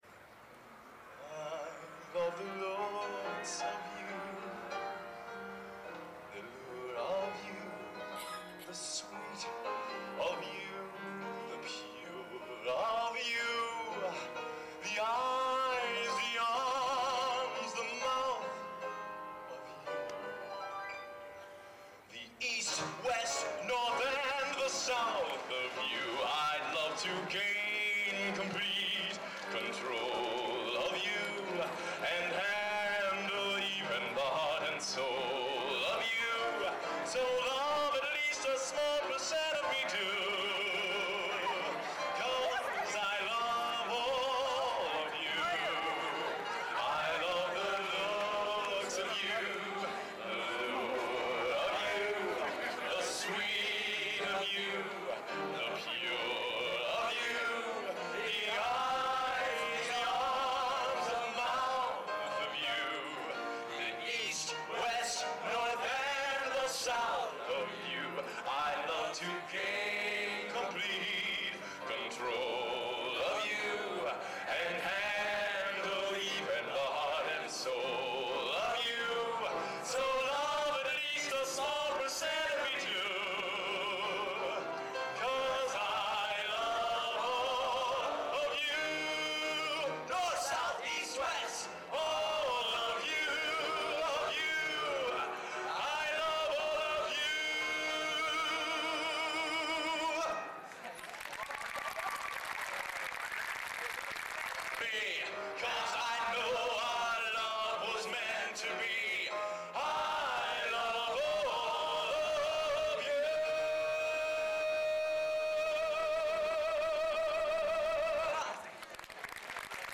Location: Purdue Memorial Union, West Lafayette, Indiana